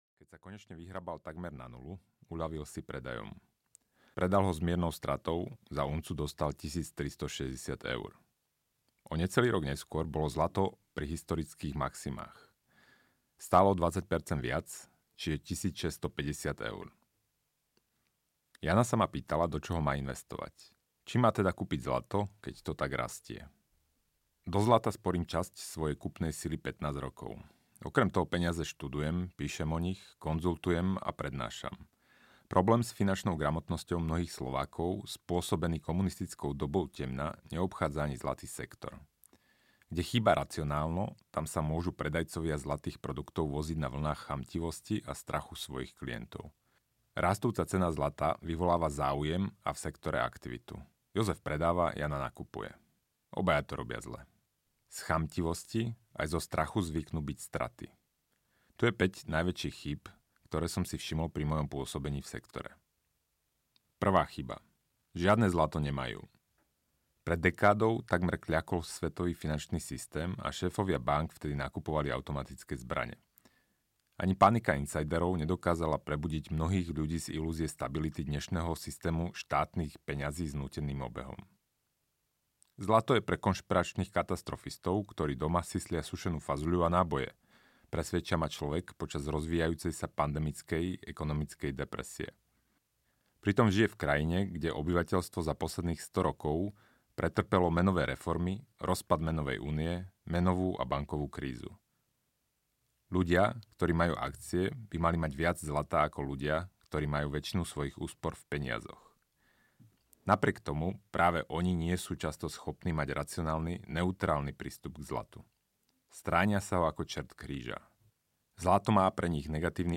Ako na zlato – Peniaze pre neveriacich audiokniha
Ukázka z knihy